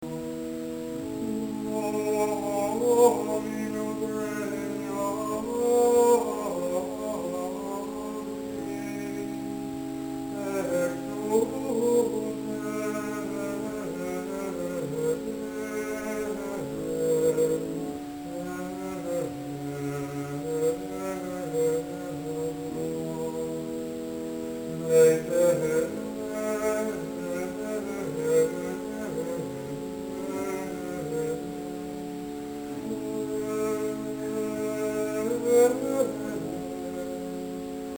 Tags: Gregorian Chants Gregorian sounds